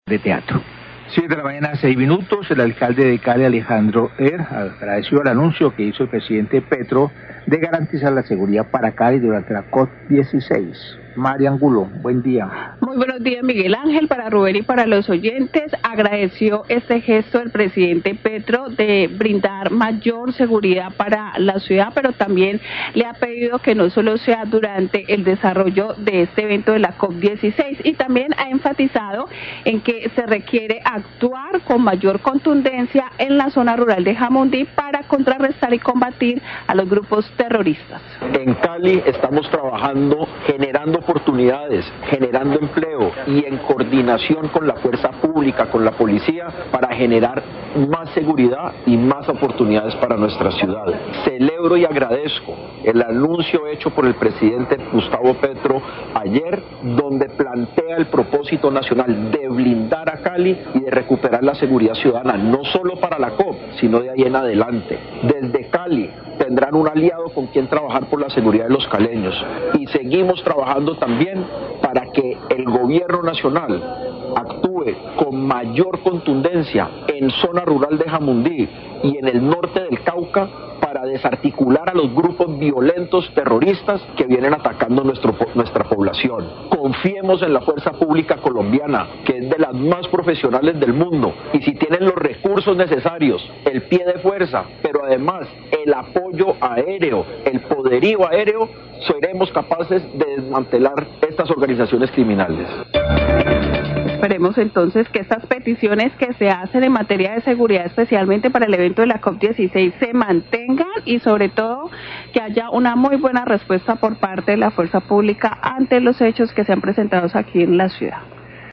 Radio
NOTICIERO RELÁMPAGO